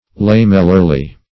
lamellarly - definition of lamellarly - synonyms, pronunciation, spelling from Free Dictionary Search Result for " lamellarly" : The Collaborative International Dictionary of English v.0.48: Lamellarly \Lam"el*lar*ly\, adv. In thin plates or scales.